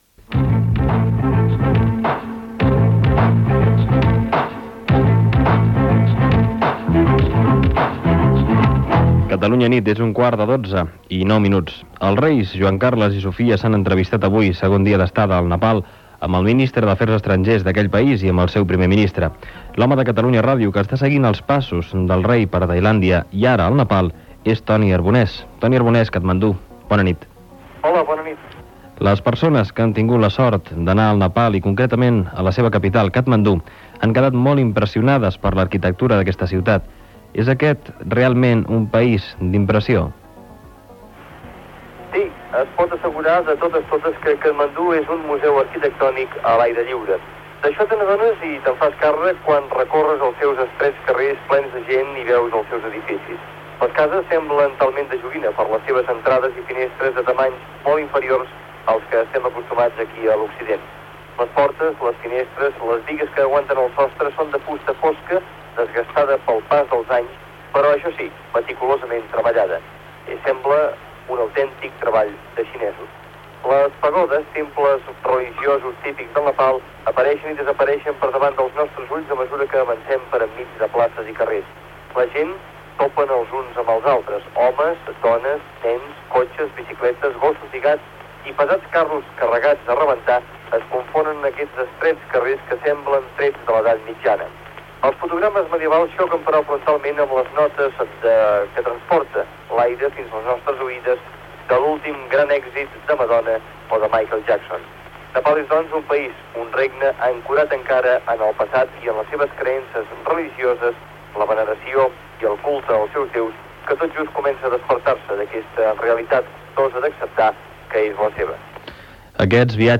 Hora, identificació del programa, informació, des de Katmandú, del segon dia de la visita dels reis d'Espanya Juan Carlos I i Sofia al Nepal
Informatiu